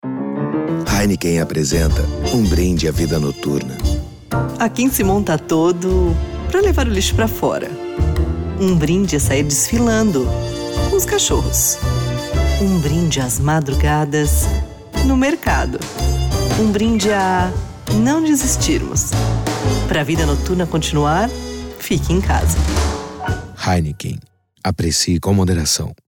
Feminino
HEINEKEN (madura, sensual)
Voz Madura 00:26